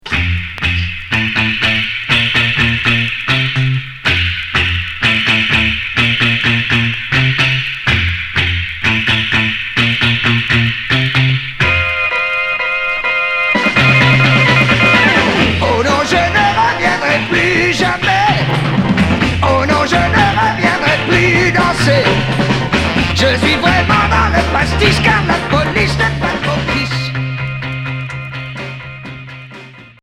Jerk